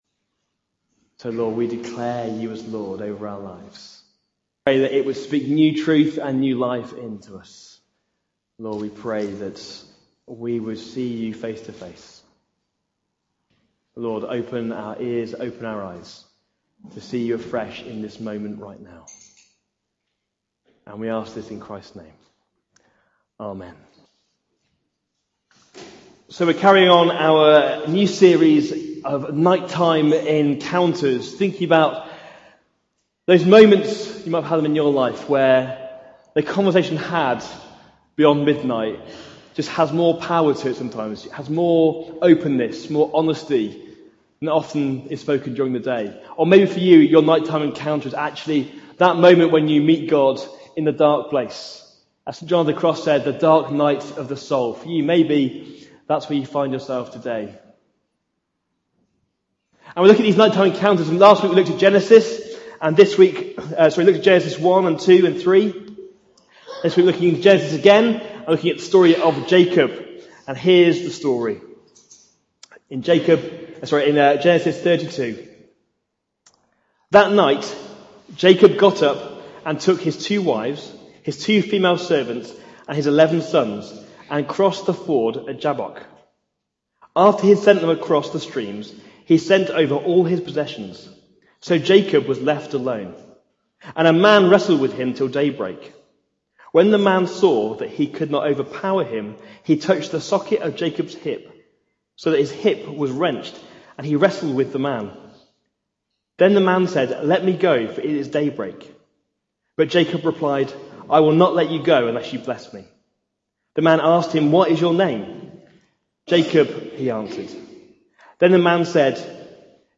Jun 24, 2019 Night-time Encounters: Part 2 MP3 SUBSCRIBE on iTunes(Podcast) Notes Sermons in this Series Looking at the encounter Jacob has with God when they wrestle all night.